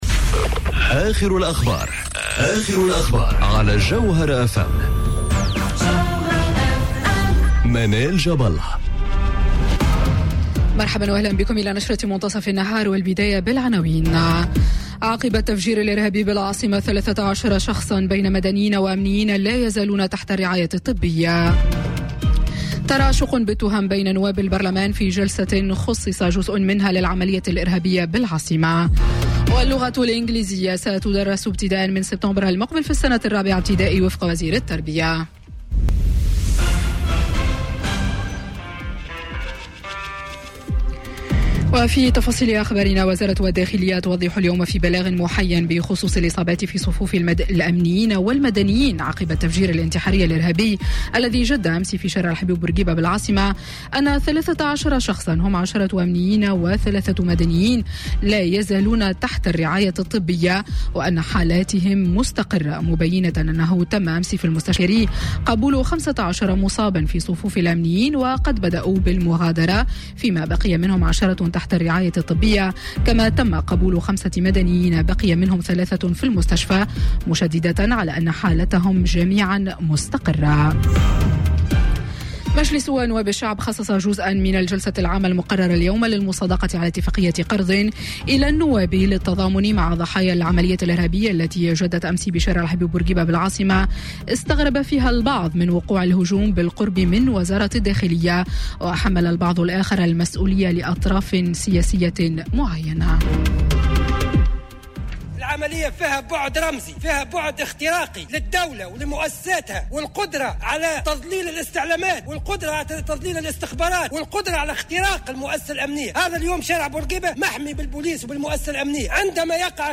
Journal Info 12h00 du mardi 30 octobre 2018